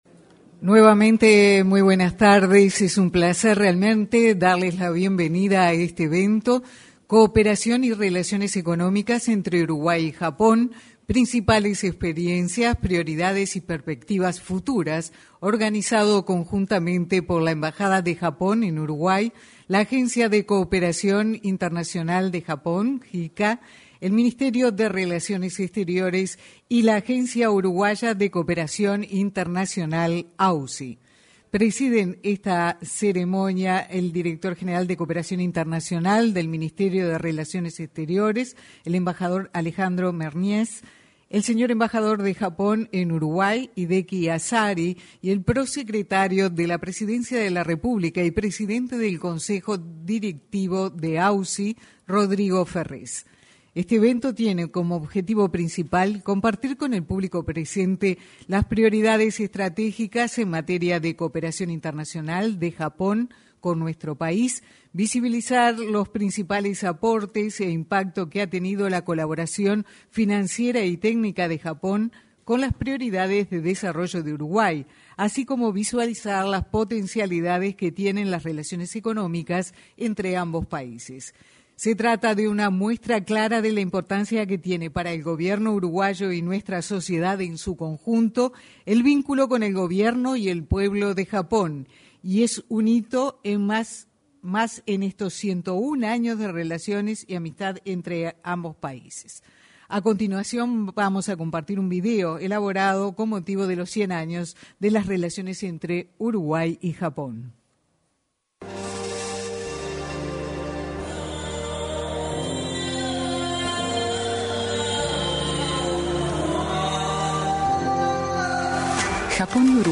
Cooperación y Relaciones Económicas entre Uruguay y Japón 18/08/2022 Compartir Facebook X Copiar enlace WhatsApp LinkedIn Este jueves 16, el director general de cooperación internacional del ministerio de relaciones exteriores, Alejandro Mernies ; el embajador de Japón en Uruguay, Hideki Asari; el prosecretario de la Presidencia, Rodrigo Ferrés, y el presidente del Consejo Directivo de la Agencia Uruguaya de Cooperación Internacional (AUCI), Mariano Berro, se expresaron en el evento Cooperación y Relaciones Económicas entre Uruguay y Japón: Experiencias, Prioridades y Perspectivas Futuras, realizado en el salón de actos de la Torre Ejecutiva.